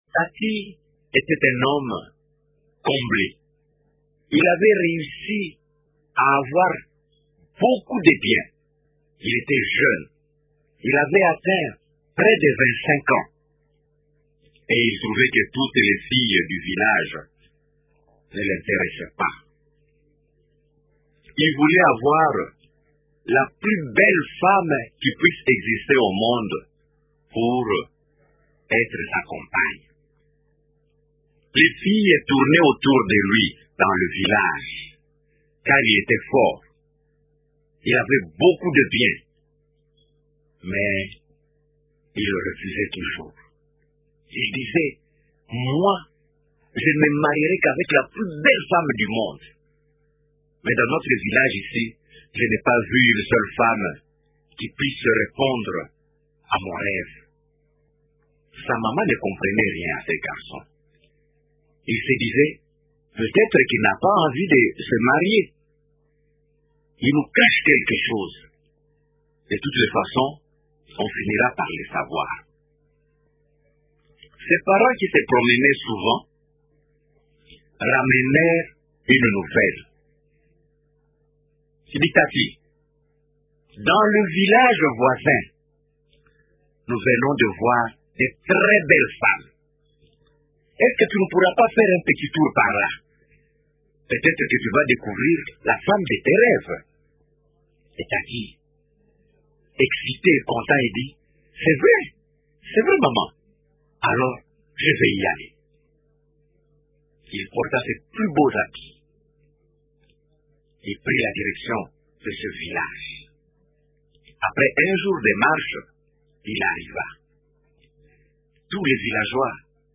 le conteur